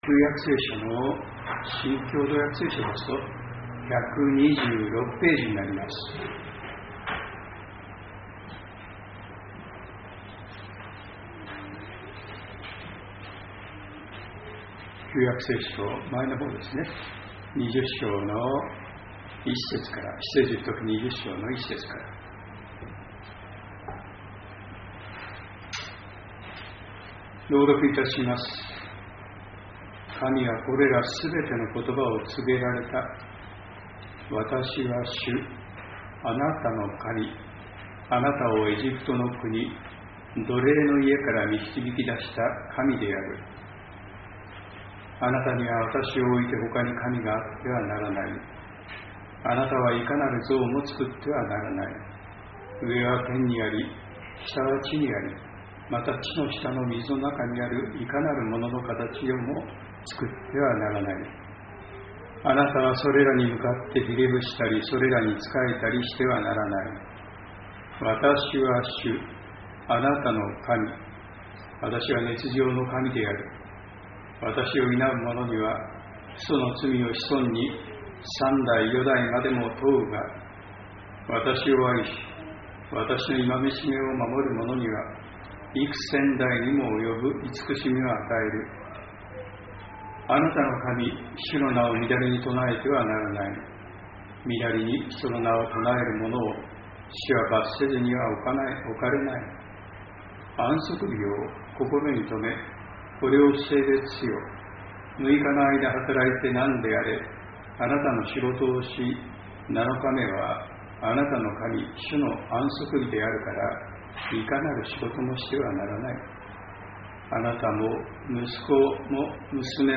栃木県鹿沼市のプロテスタント教会。
礼拝説教アーカイブ 日曜 朝の礼拝